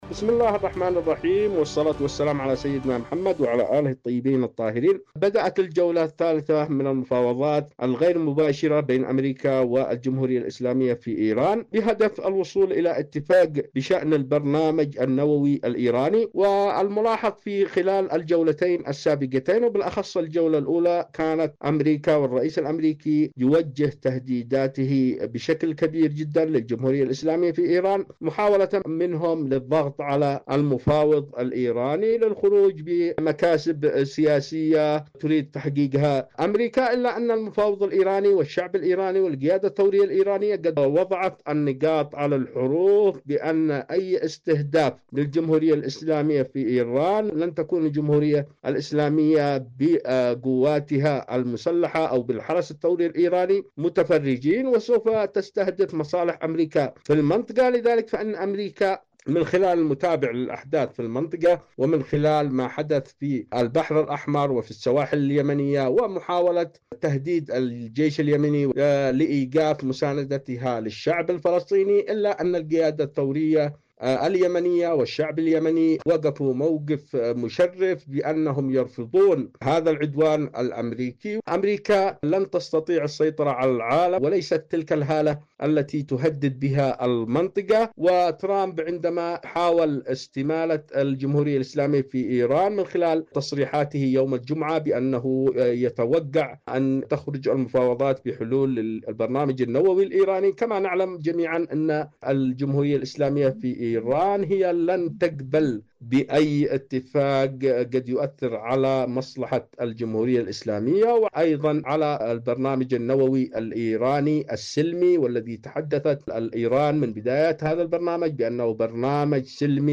مقابلة